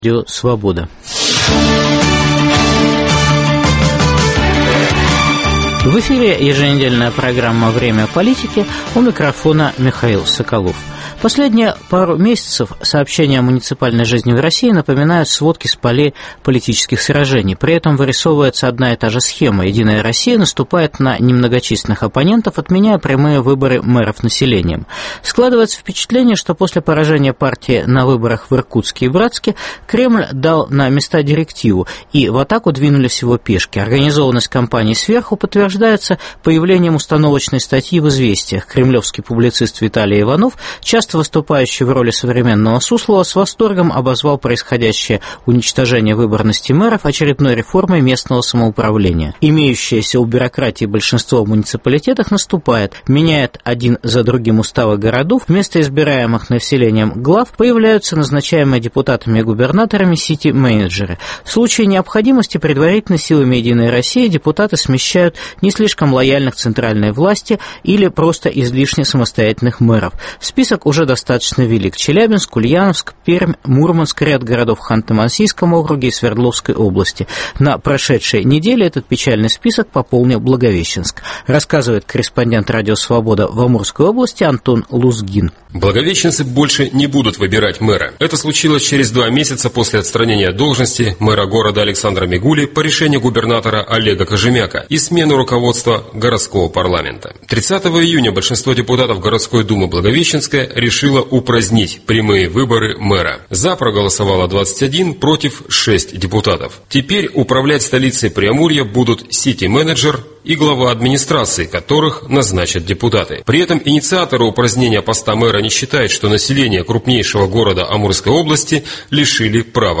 Политика "всенародных" диктатур: сходства, различия, взаимовлияние. Беседа с лидером Объединенной гражданской партии Беларуси Анатолием Лебедько.